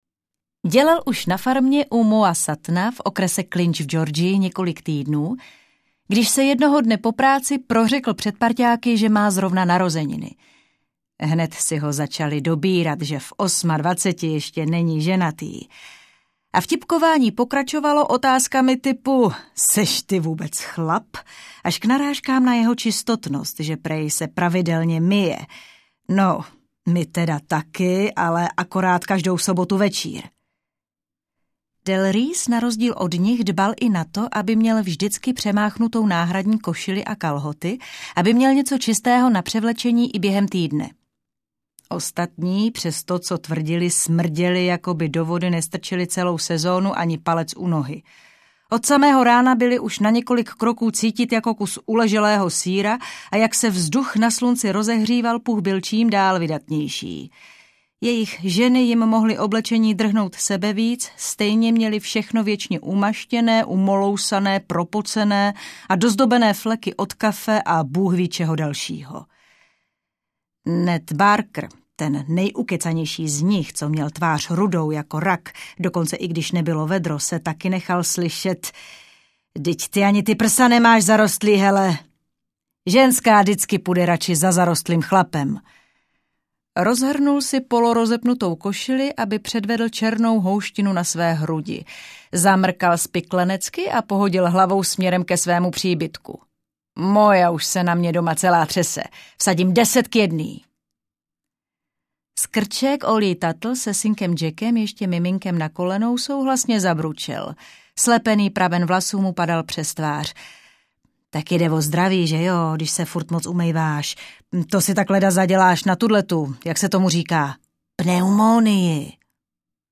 Vlaštovčí kopec audiokniha
Ukázka z knihy
vlastovci-kopec-audiokniha